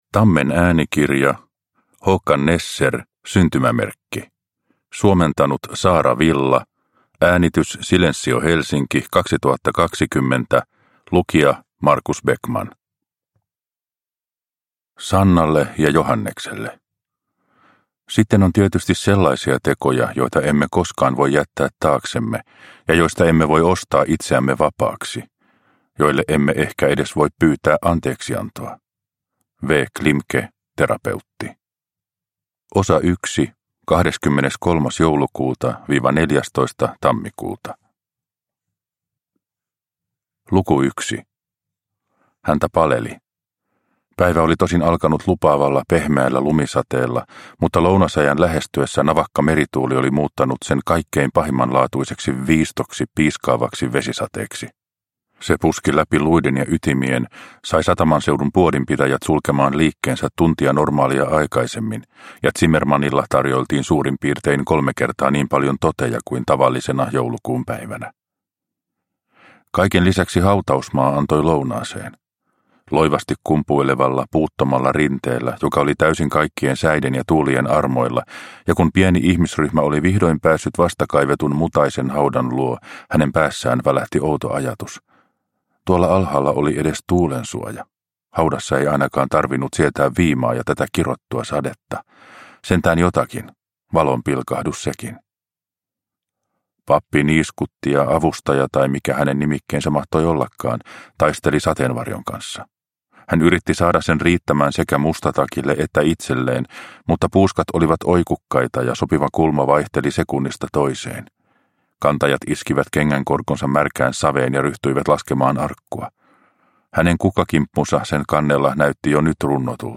Syntymämerkki – Ljudbok – Laddas ner